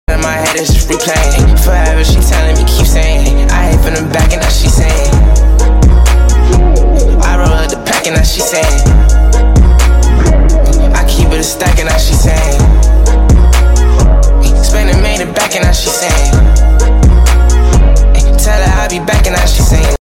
The 2017 Mercedes Benz GLA45 AMG sound effects free download